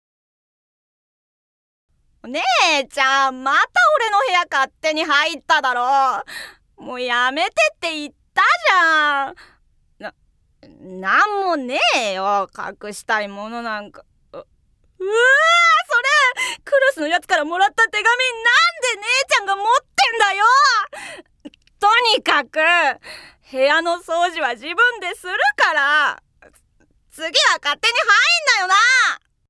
◆ツンデレ中学生男子◆